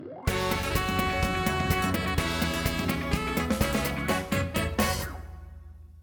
Ripped from game
Fair use music sample